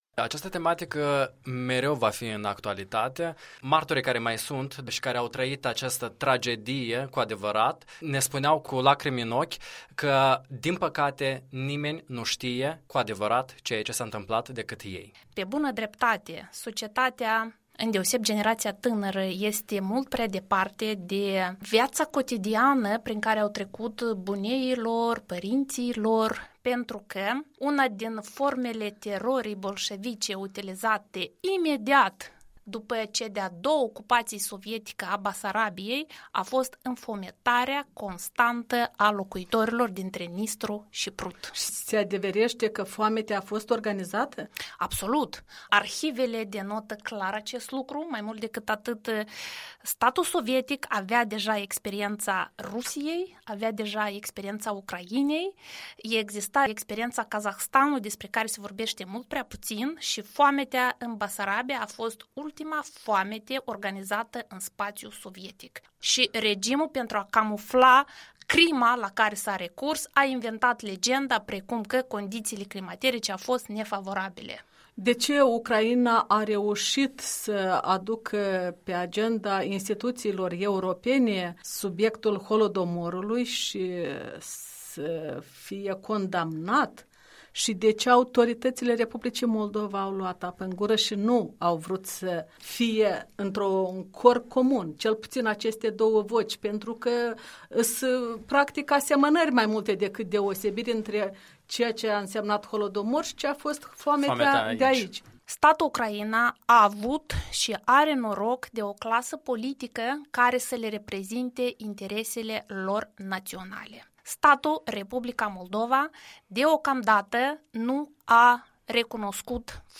Un documentar